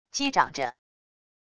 击掌着wav音频